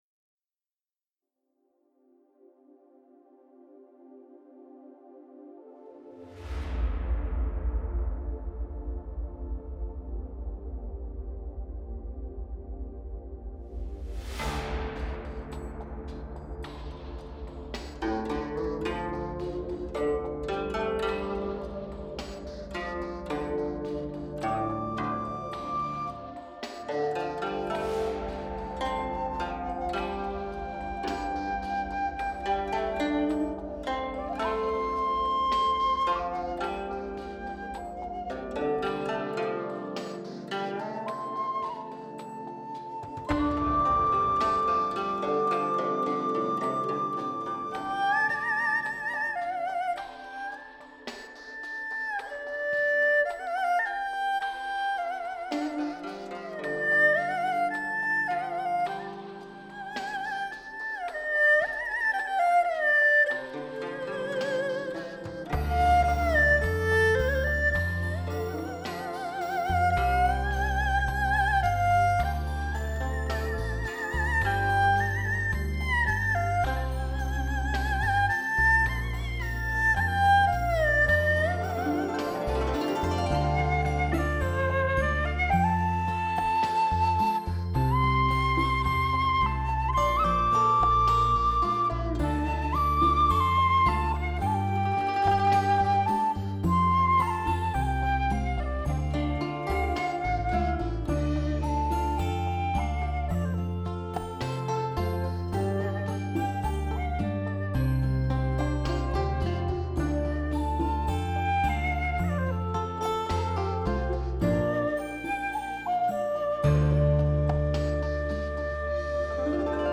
汇集十四首经典红歌，重新编配、演奏、录制。
清爽的配乐构架，极大的扩展了音韵的感染力，使整张专辑旋律熟悉却又新颖。